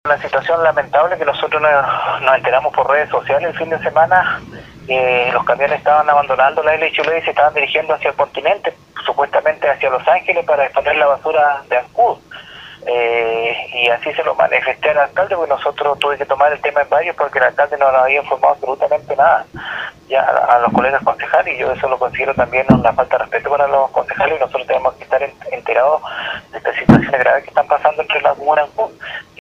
08-CONCEJAL-ALEX-MUNOZ.mp3